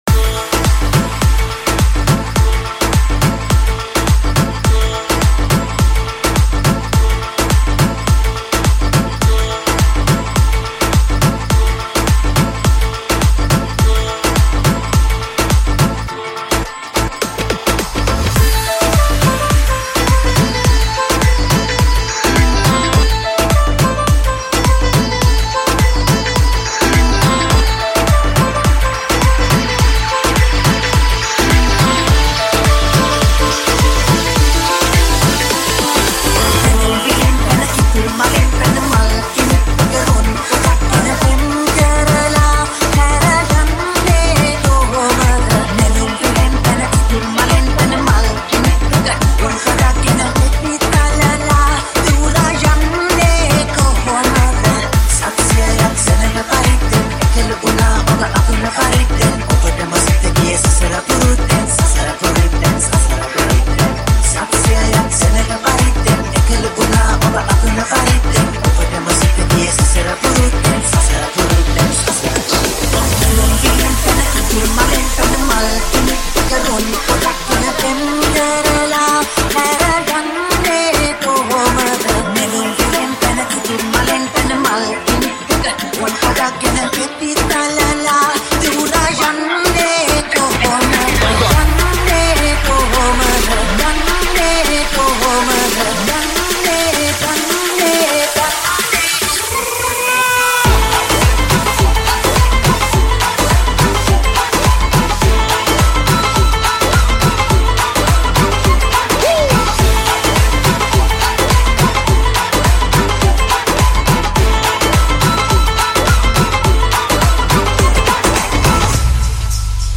High quality Sri Lankan remix MP3 (4).